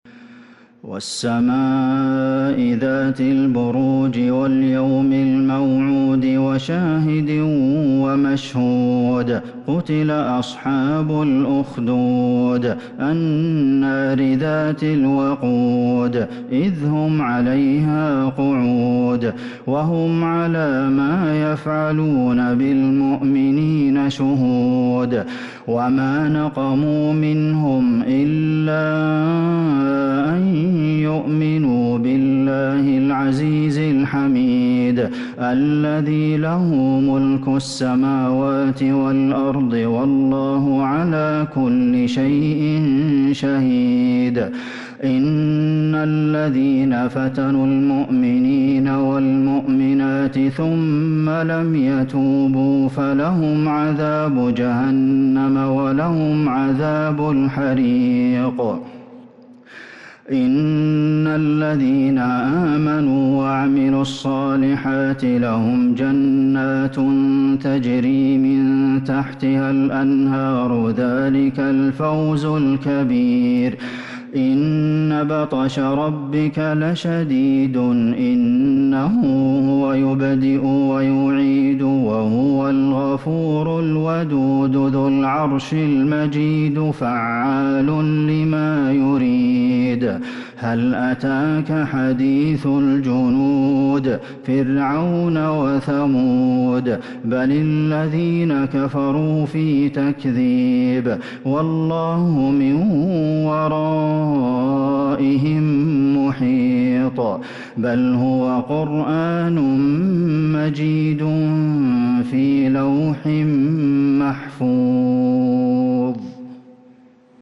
سورة البروج Surat Al-Burooj من تراويح المسجد النبوي 1442هـ > مصحف تراويح الحرم النبوي عام 1442هـ > المصحف - تلاوات الحرمين